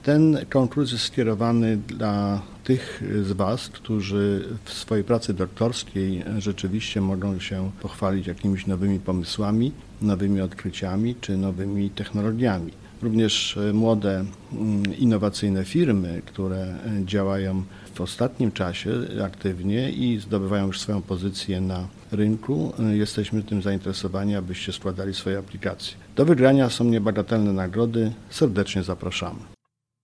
Do udziału w konkursie zaprasza marszałek województwa mazowieckiego Adam Struzik.